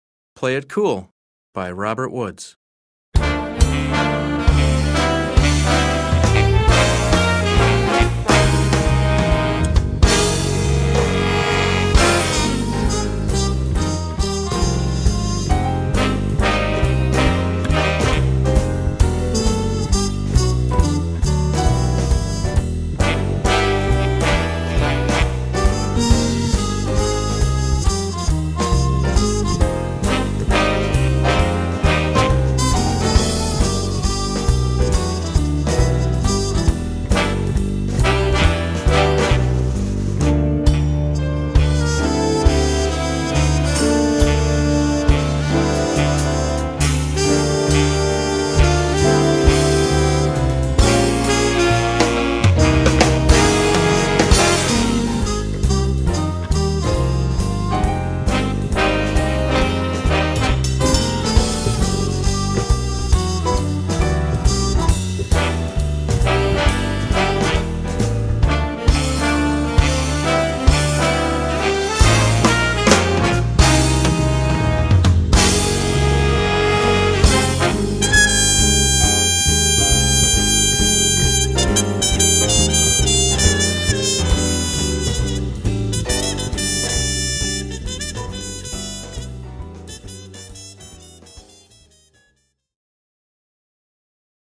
Voicing: Combo Quintet